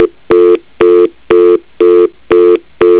dial tone (like a "fast busy"), distinct from the regular
message.au